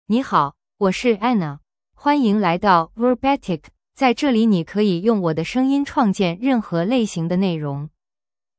FemaleChinese (Mandarin, Simplified)
AnnaFemale Chinese AI voice
Anna is a female AI voice for Chinese (Mandarin, Simplified).
Voice sample
Female
Anna delivers clear pronunciation with authentic Mandarin, Simplified Chinese intonation, making your content sound professionally produced.